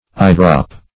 Eyedrop \Eye"drop"\, n.